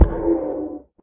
guardian_hit2.ogg